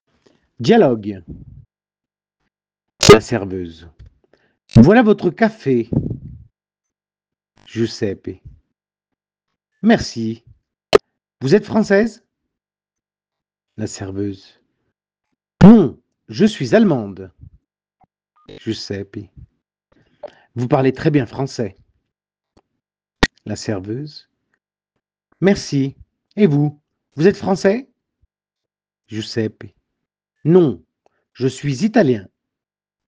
By the end of this lesson, you’ll be ready to understand and use être confidently in real-life conversations — just like the one we’ll see between a tourist and a waitress in Brussels.
6. Dialogue Example (Paris Café ☕)